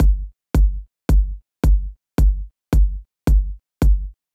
35 Kick.wav